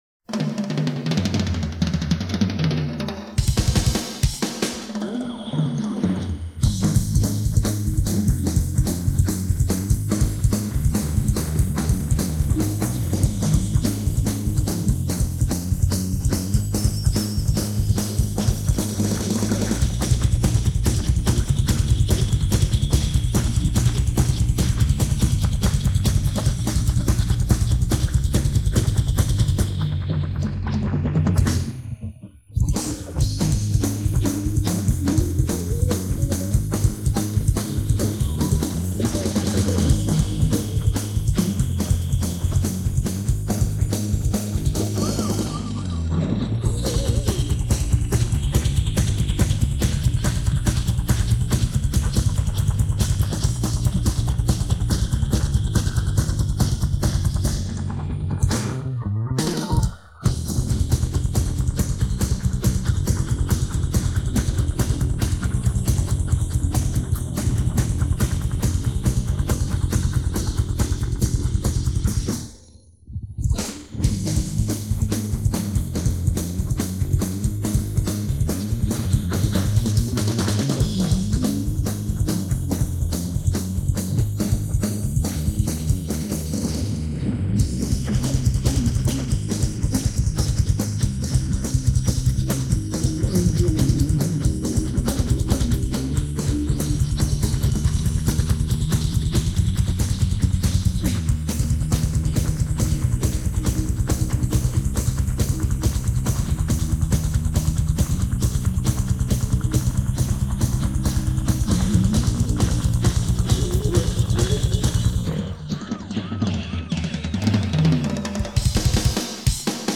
Feitas no Izotope RX...